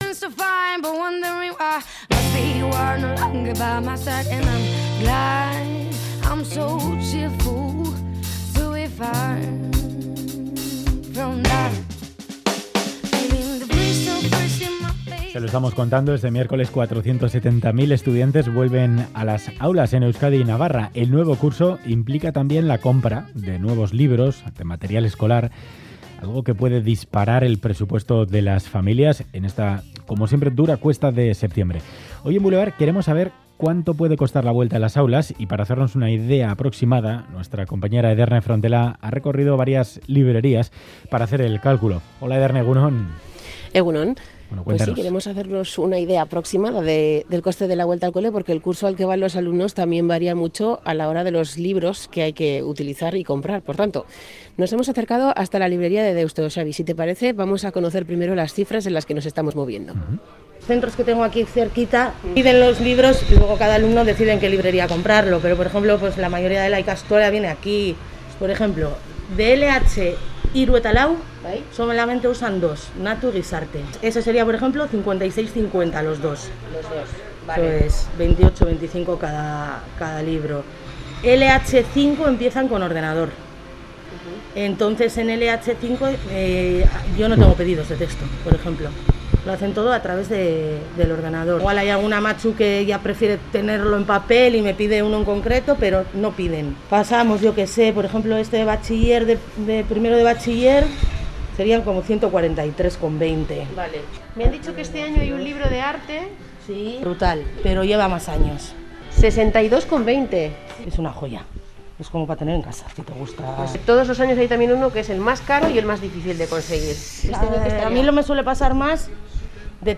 Reportaje para analizar cuanto cuesta la vuelta al cole a las familias.